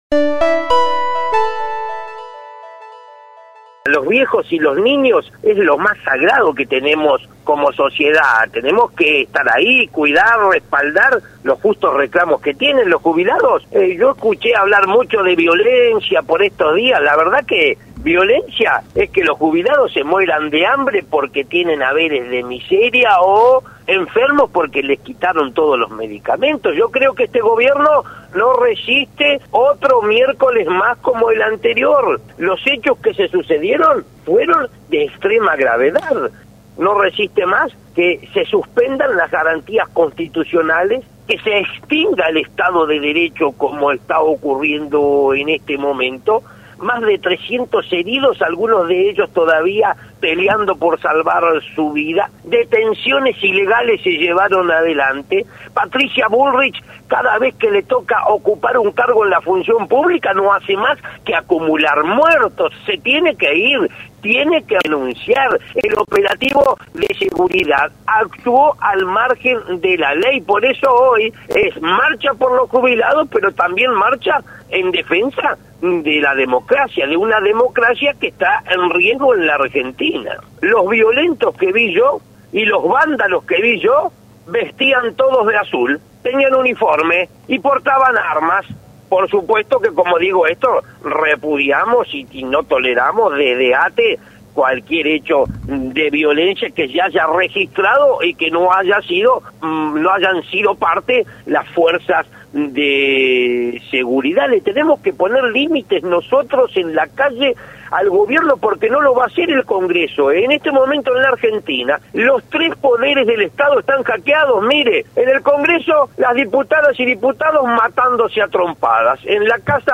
El secretario general de la Asociación de Trabajadores del Estado (ATE), Rodolfo Aguiar mantuvo un contacto con LT3 en donde se refirió a la participación de los estatales en la movilización de los jubilados de este miércoles al Congreso Nacional.